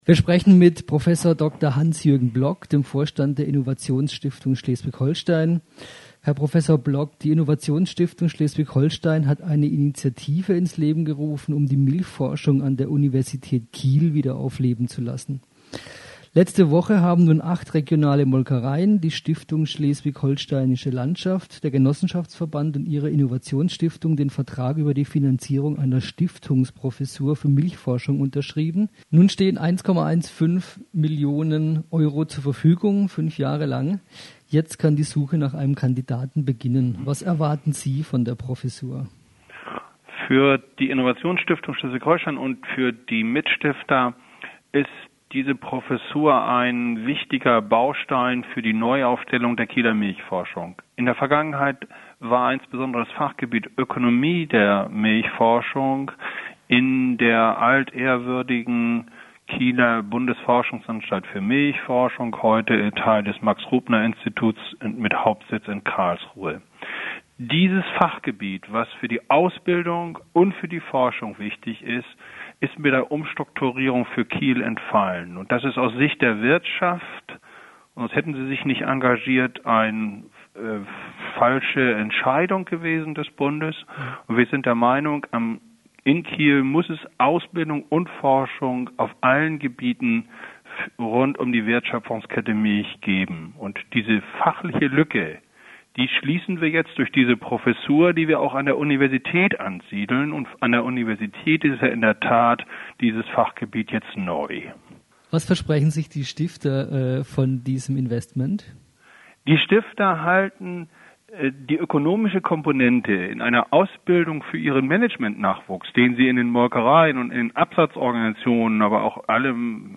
Telefoninterview